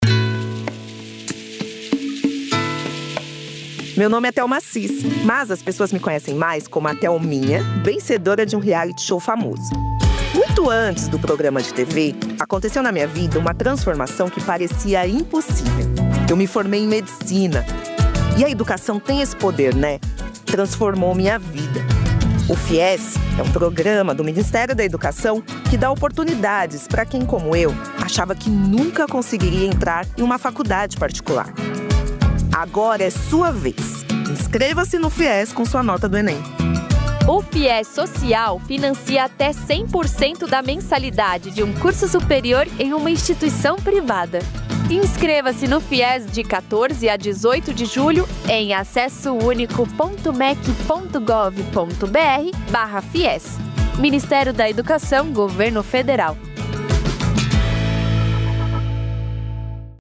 Spots e Jingles produzidos pela rede gov e por órgãos do governo federal.